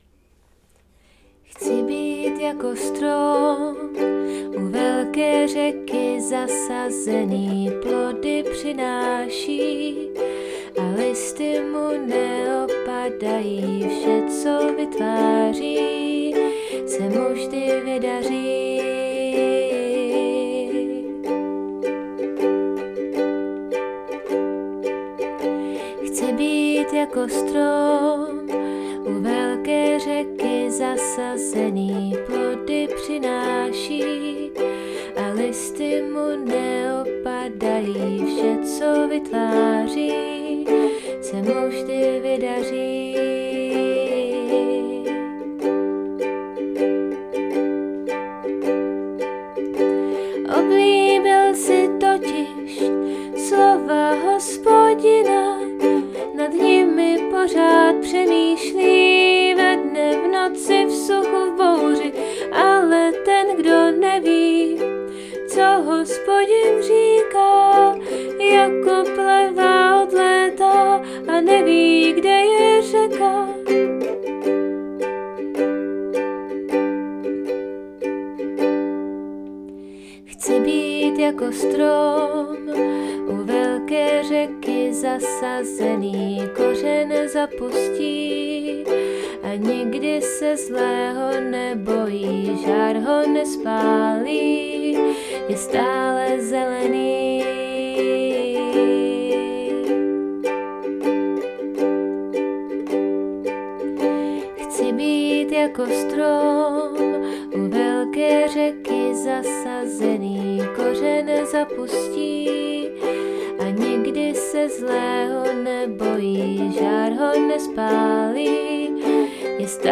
V prezentaci je i písnička napsaná k této příležitosti :)
PÍSNIČKA